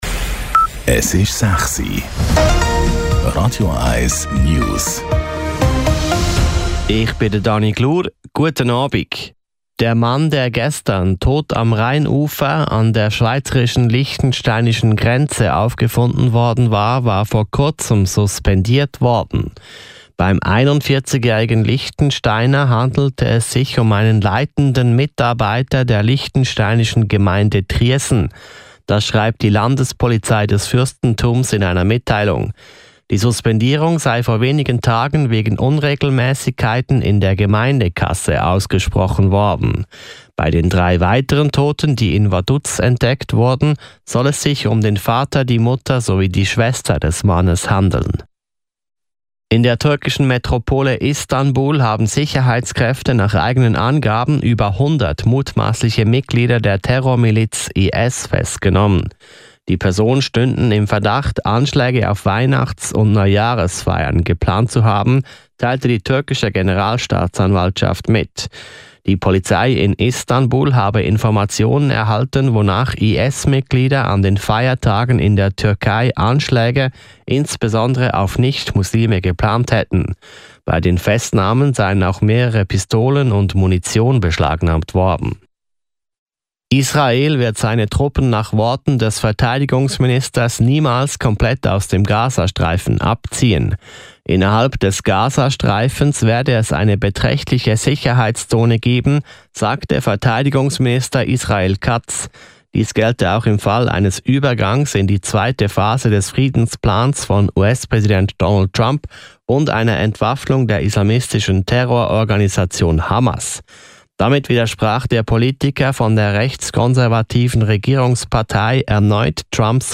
Die aktuellsten News von Radio 1 - kompakt, aktuell und auf den Punkt gebracht.